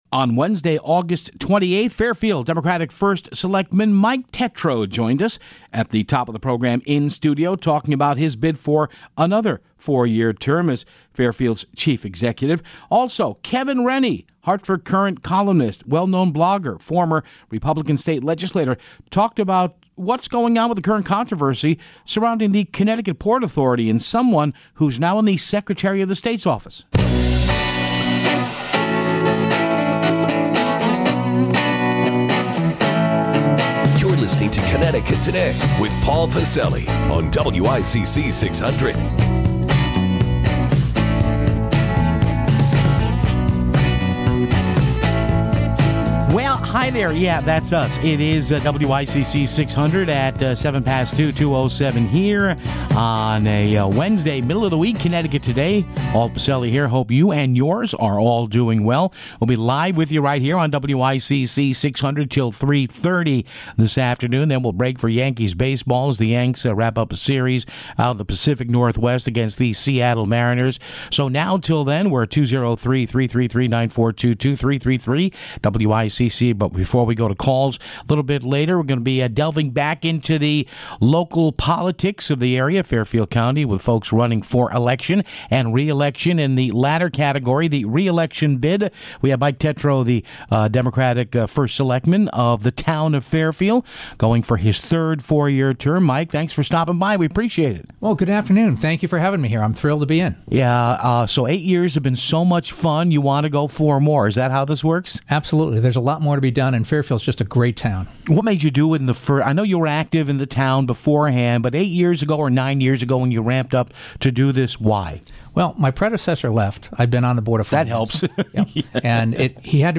Fairfield First Selectman Michael Tetreau In-Studio